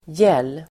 Uttal: [jel:]